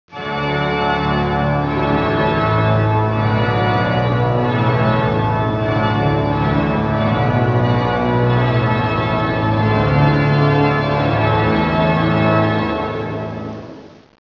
música, Resultado final
organo_iglesia.wav